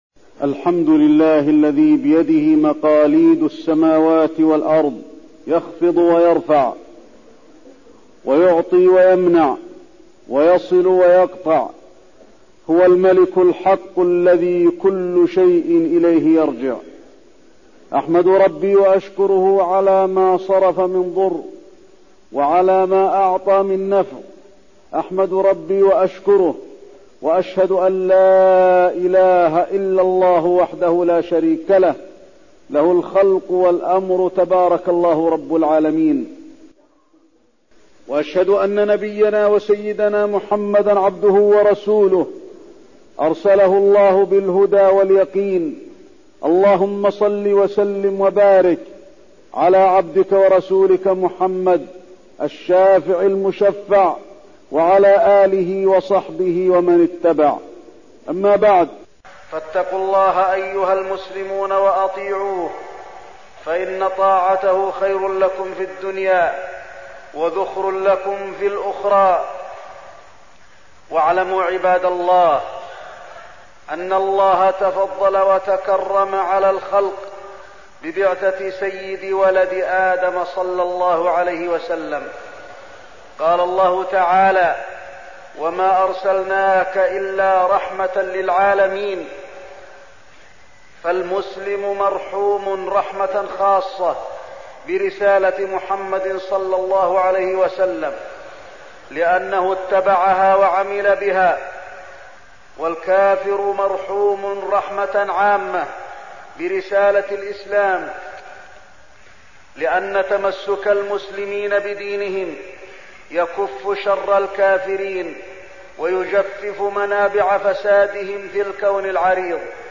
تاريخ النشر ١٢ ربيع الثاني ١٤١٨ هـ المكان: المسجد النبوي الشيخ: فضيلة الشيخ د. علي بن عبدالرحمن الحذيفي فضيلة الشيخ د. علي بن عبدالرحمن الحذيفي السنة النبوية وبيان فضلها